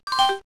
Index of /phonetones/unzipped/Kyocera/KX2-Koi/shutter